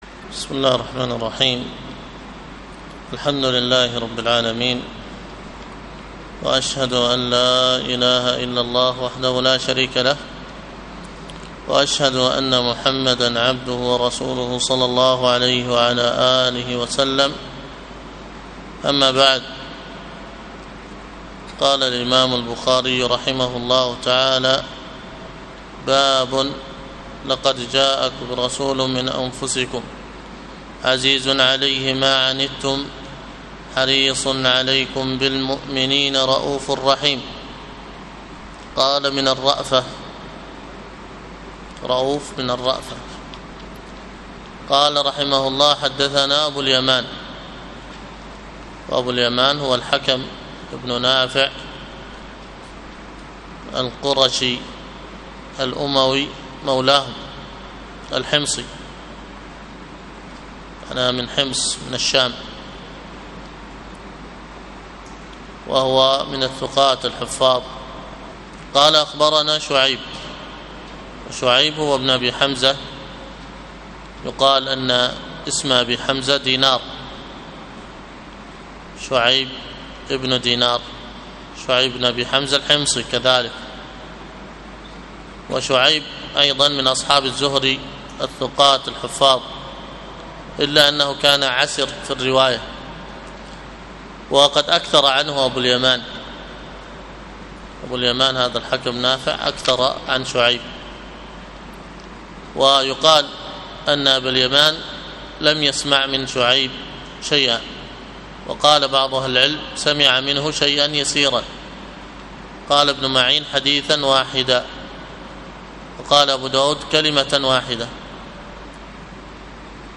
الدرس في حاشية الأصول الثلاثة لابن قاسم 26، تابع : رسالته صلى الله عليه وسلم. انتقاله صلى الله عليه وسلم إلى الرفيق الأعلى. الدليل على موته صلى الله عليه وسلم.